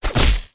Heavy_Sword3.wav